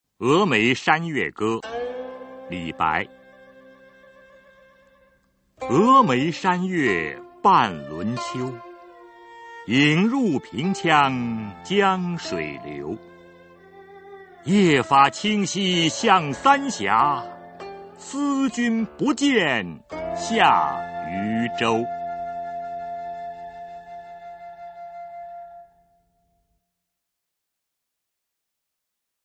[隋唐诗词诵读]李白-峨眉山月歌 唐诗吟诵